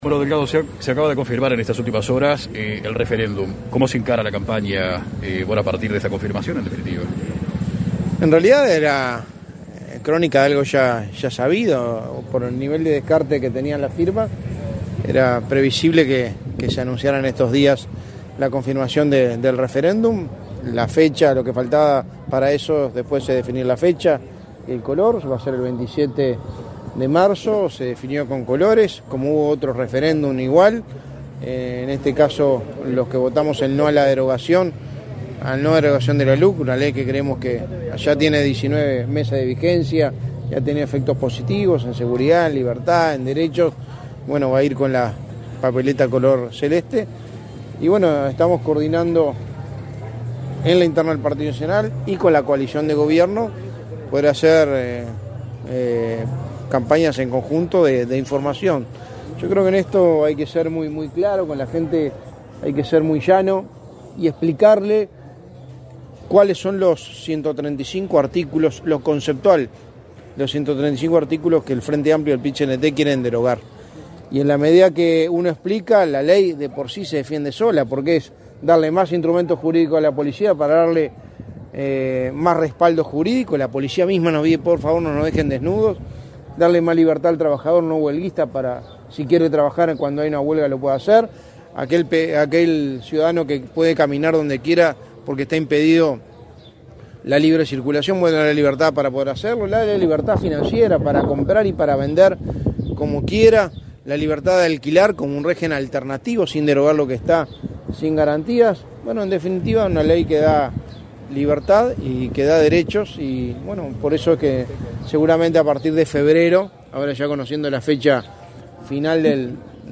Declaraciones a la prensa del secretario de Presidencia, Álvaro Delgado
Declaraciones a la prensa del secretario de Presidencia, Álvaro Delgado 09/12/2021 Compartir Facebook X Copiar enlace WhatsApp LinkedIn Tras participar en la inauguración de un Centro Pyme en San José, este 9 de diciembre, el secretario de la Presidencia, Álvaro Delgado, efectuó declaraciones a la prensa.